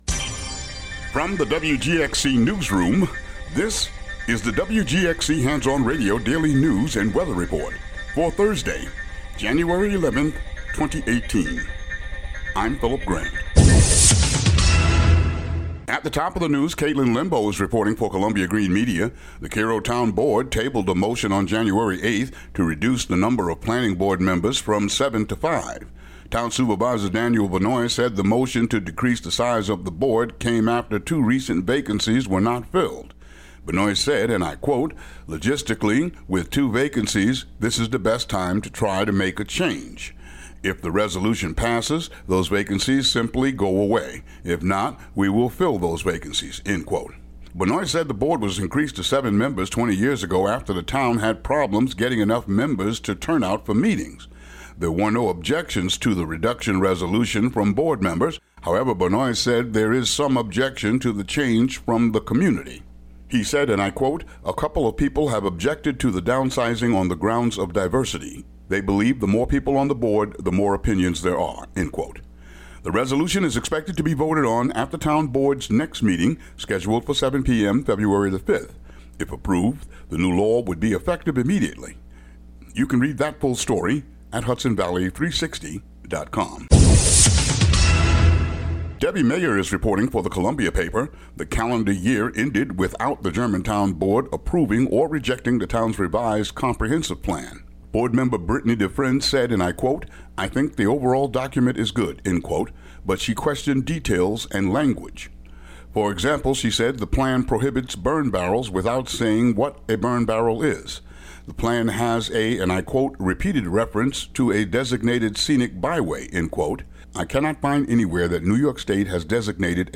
Daily local news for Thu., Jan. 11.